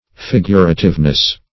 Fig"ur*a*tive*ness, n.